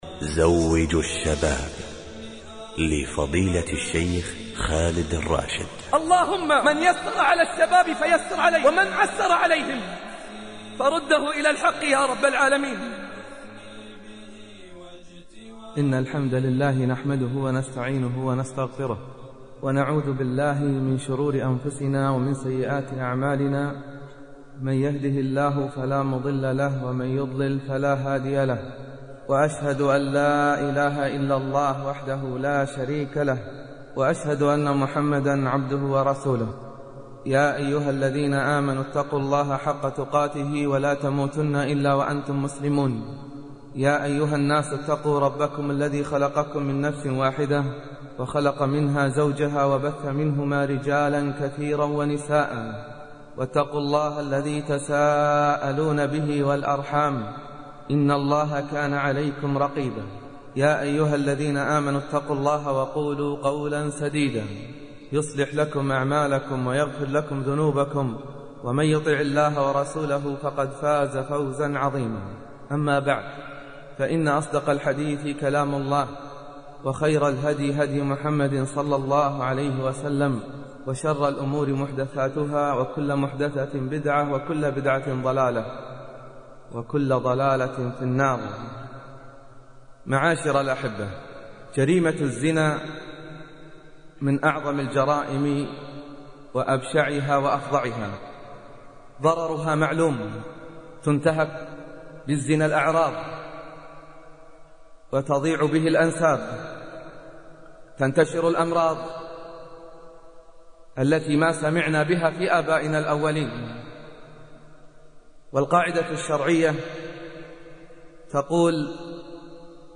المحاضرات الصوتية